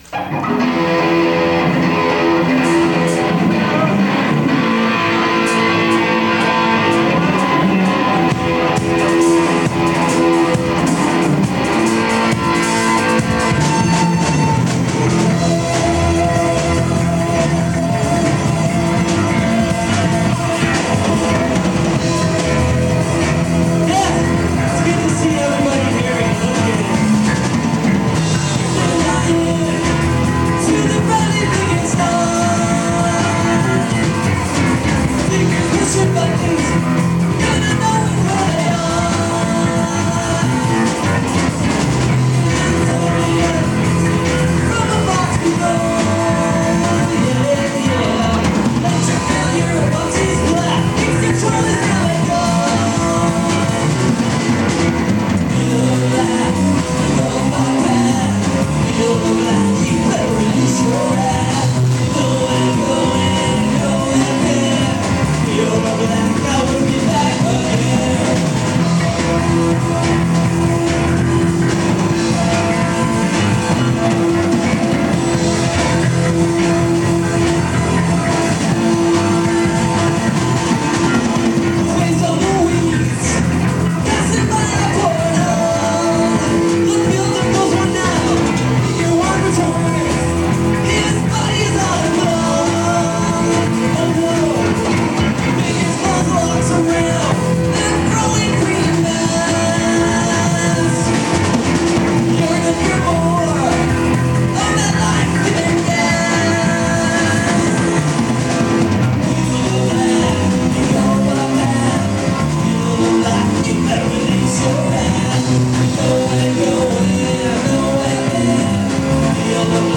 Live performances were guaranteed to experience a 20% tempo acceleration